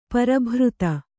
♪ para bhřta